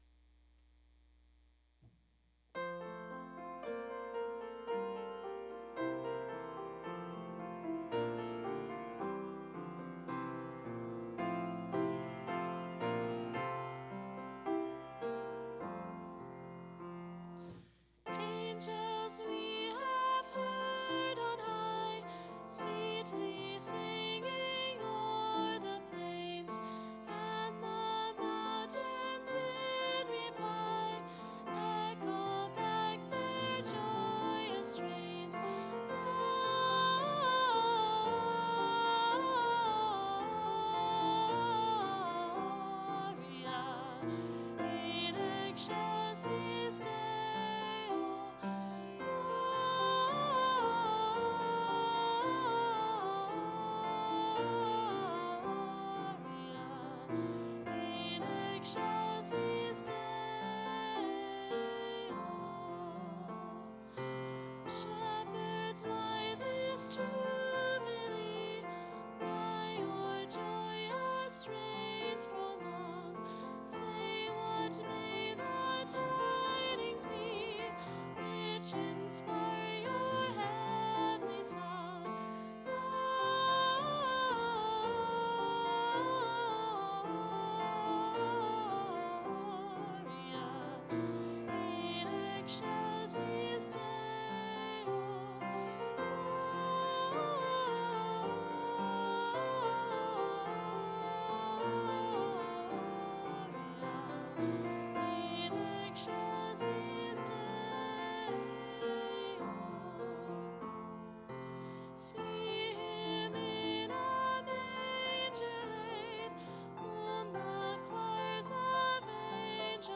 Click here for practice track